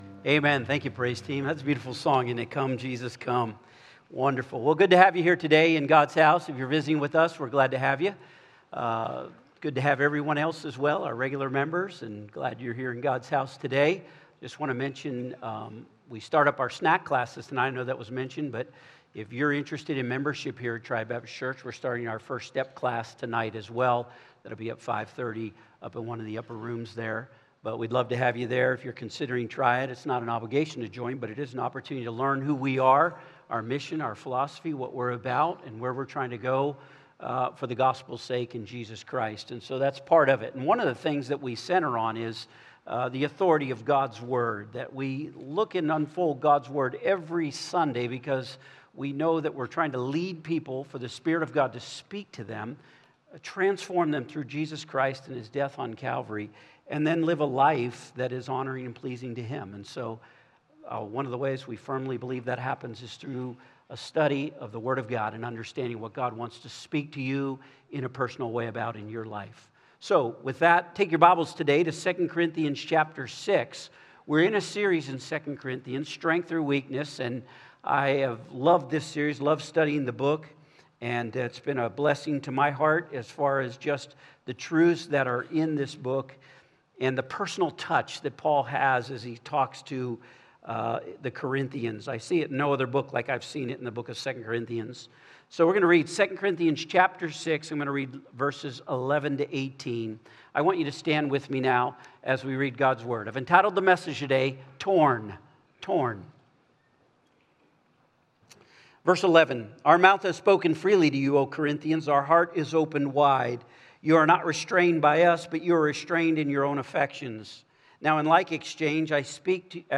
Sermons
Sermon Archive